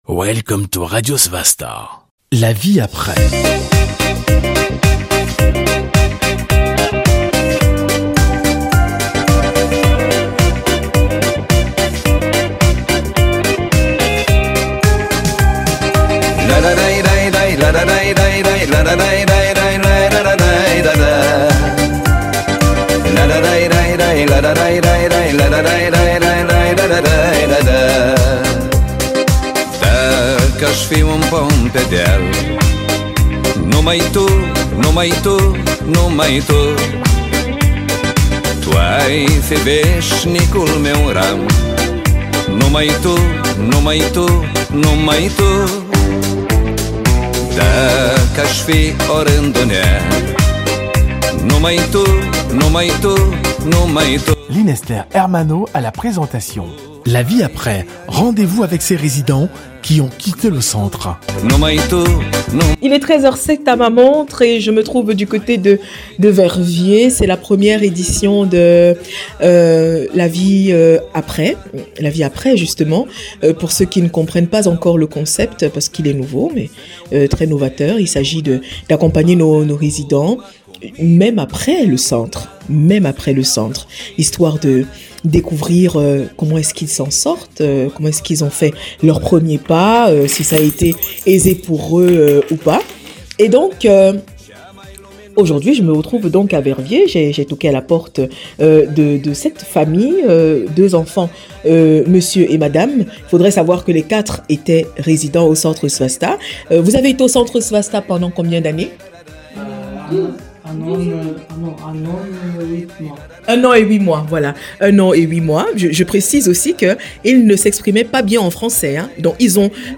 Il nous accueille avec joie, dans la chaleur de leur nouvelle demeure🏡, qui, on l’imagine; n’est pas une caravane☺.